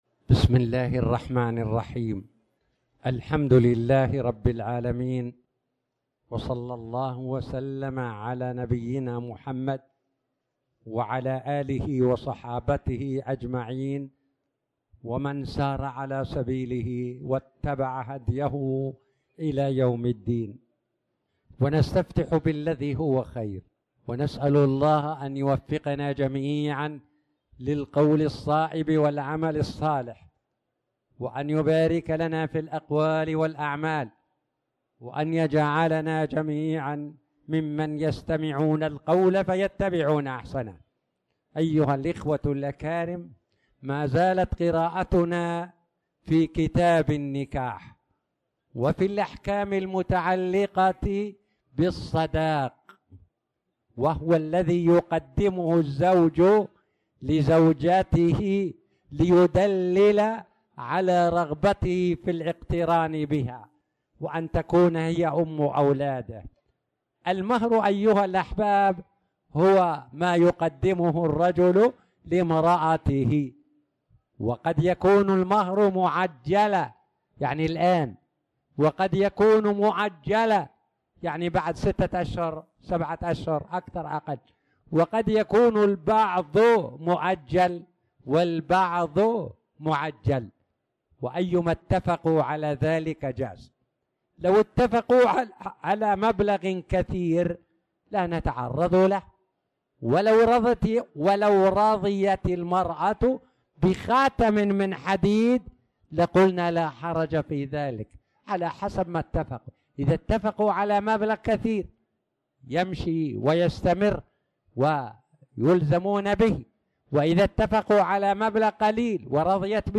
تاريخ النشر ٨ جمادى الأولى ١٤٣٨ هـ المكان: المسجد الحرام الشيخ